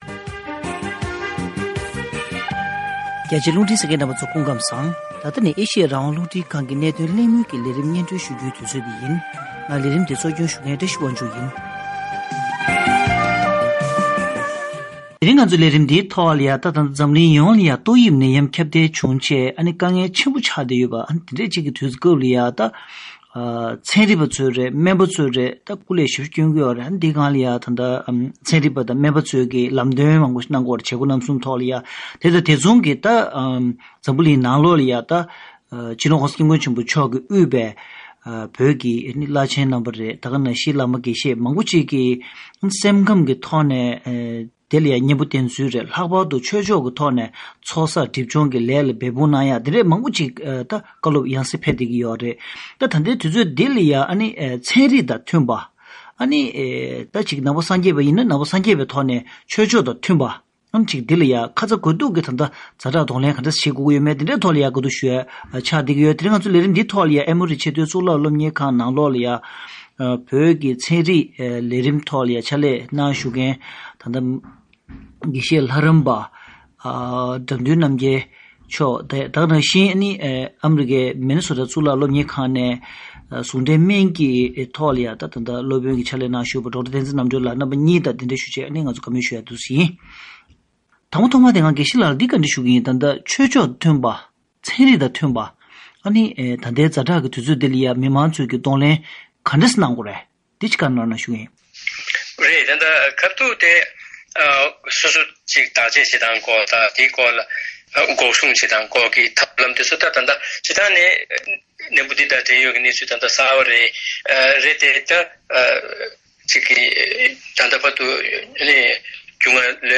༄༅།།ཐེངས་འདིའི་གནད་དོན་གླེང་མོལ་གྱི་ལས་རིམ་ནང་།